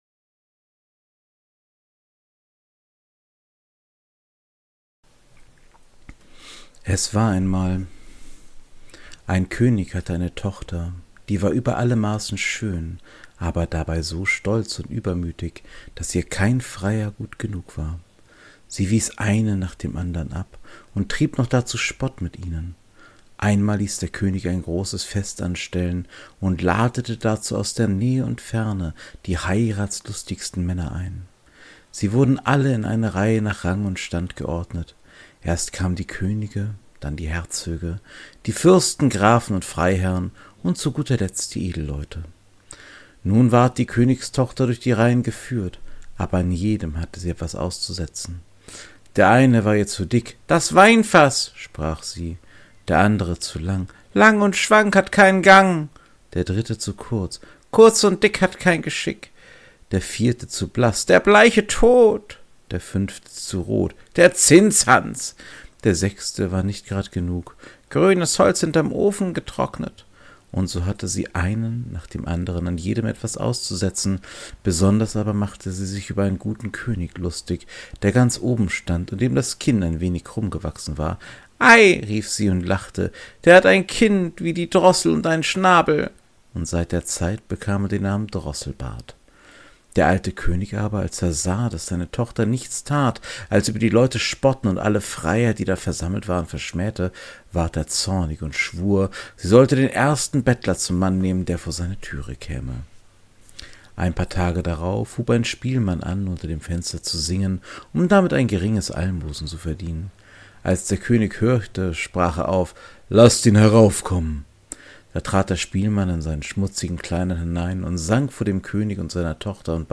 In diesem kleinen Podcast Projekt lese ich Märchen vor.